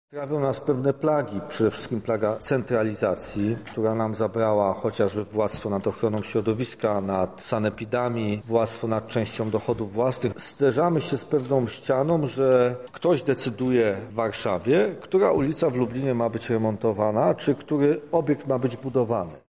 Trudno żeby 38 milionowym krajem zarządzać z jednej willi na Żoliborzu -mówi prezydent Sopotu Jacek Karnowski, prezes zarządu ruchu samorządowego: